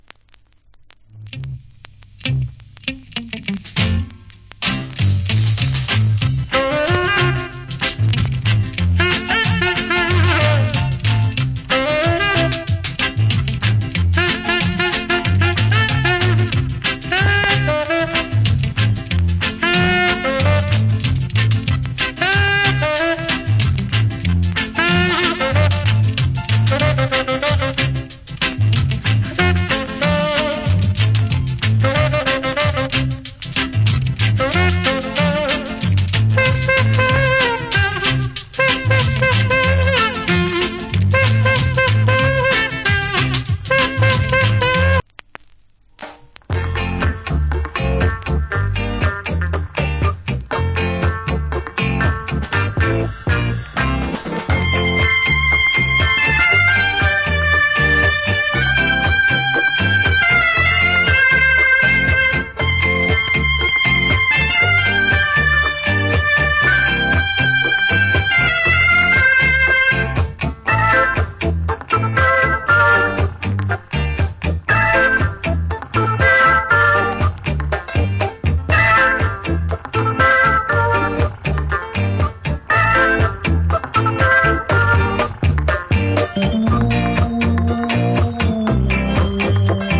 EARLY REGGAE INSTRUMENTAL
Wicked Early Reggae Inst.
EARLY REGGAE